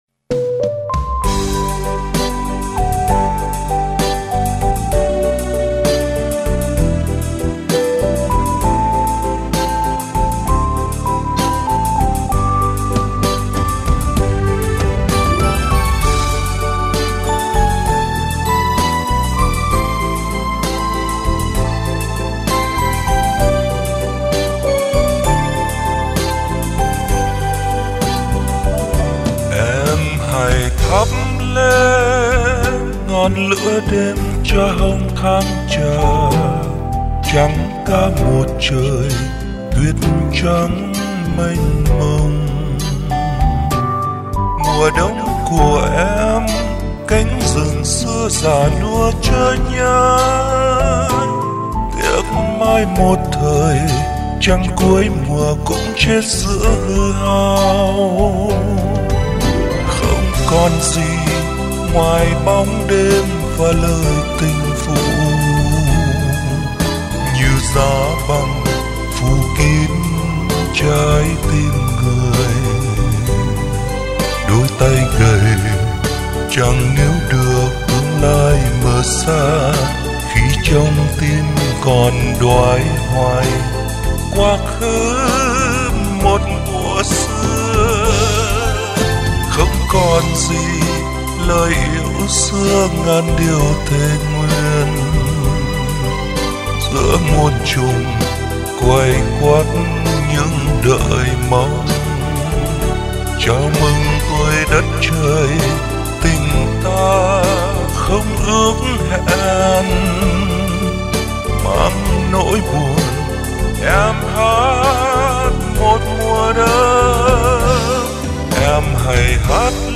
(Tiếng Hát Từ Phòng Tắm)